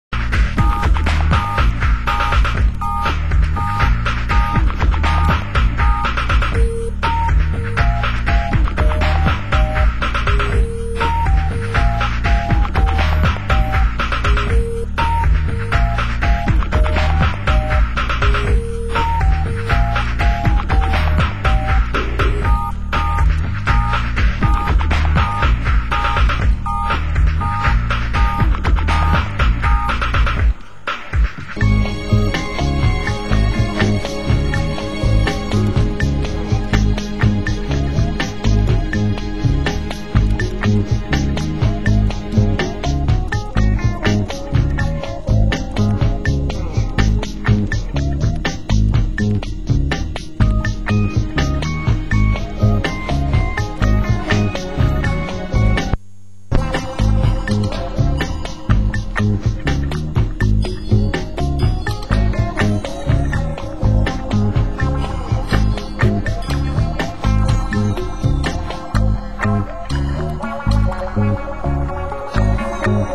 Genre: Trip Hop